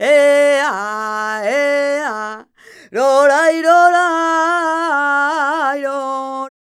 46b20voc-c#.aif